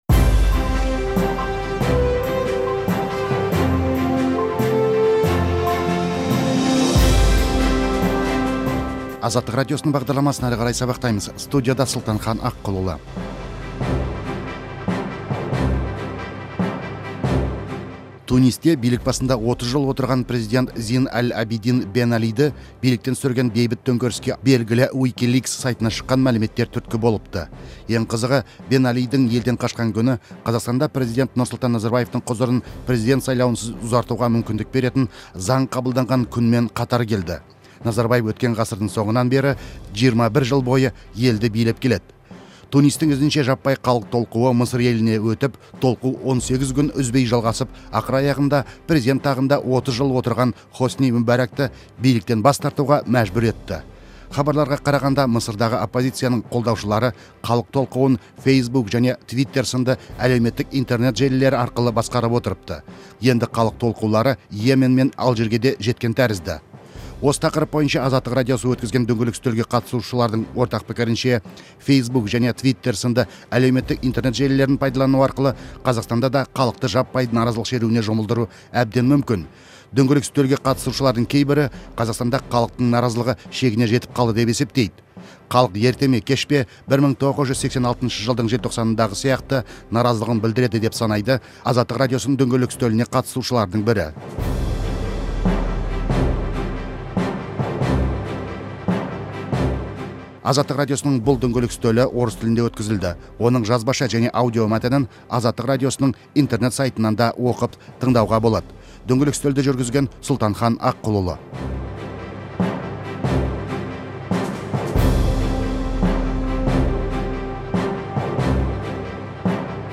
Әлеуметтік желілерге қатысты талқылауды тыңдаңыз